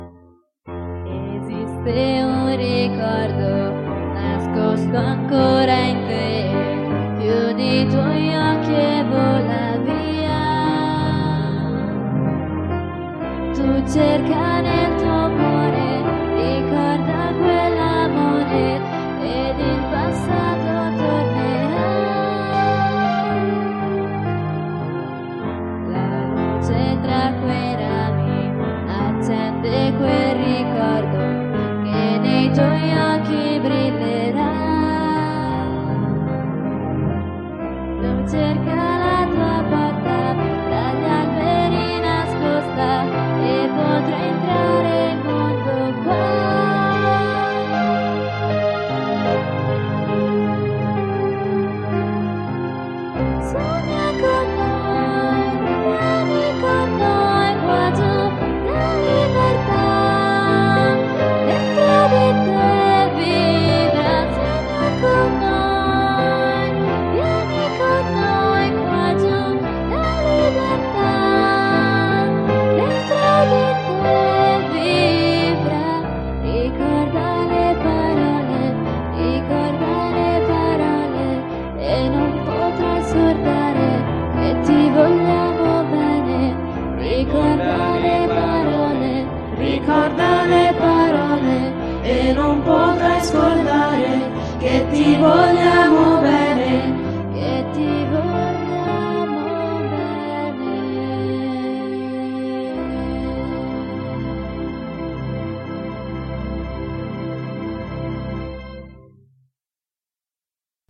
colonna sonora